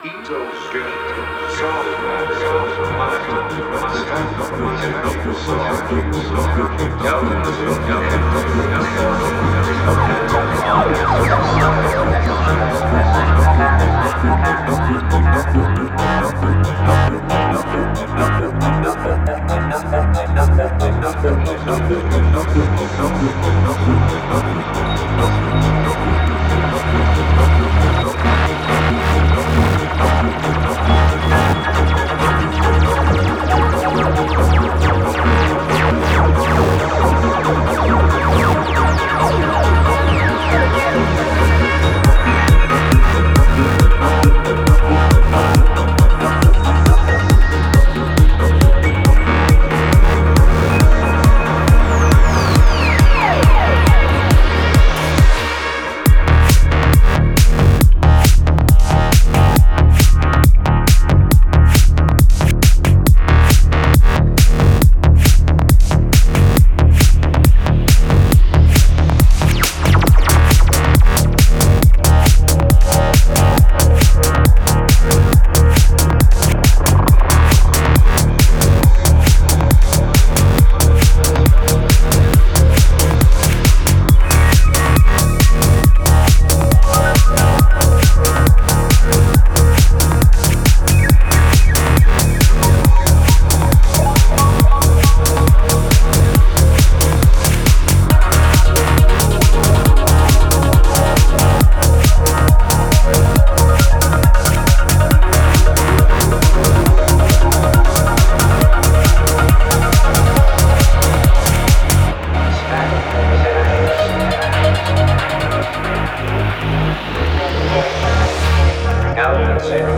Genre: Goa, Psychedelic Trance.